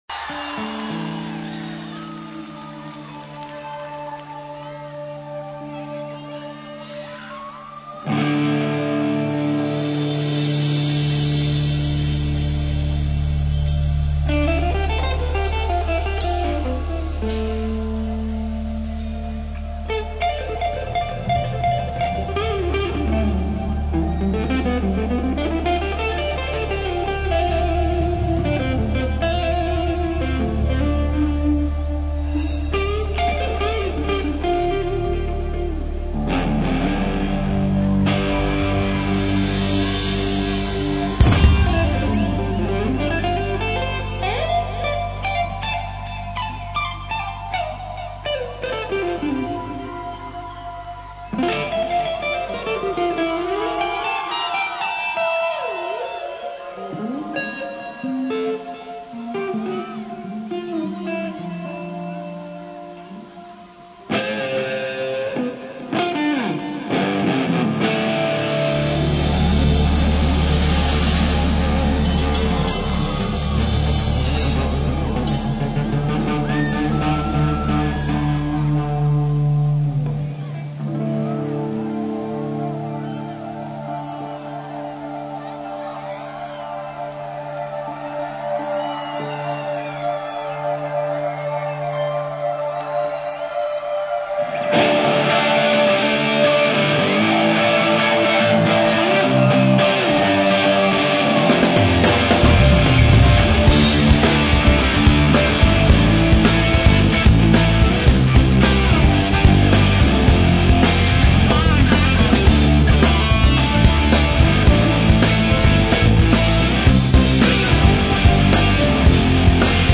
вокал, гитара
ударные, перкуссия, гитара